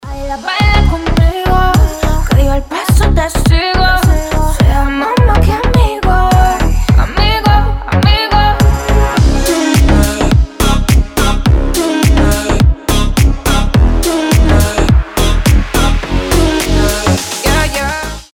• Качество: 320, Stereo
заводные
Dance Pop
басы
house